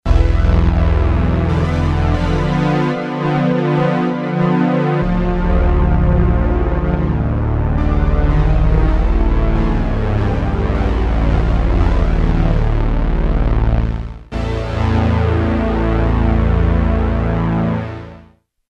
demo HEAR arpeggiator
Class: Synthesizer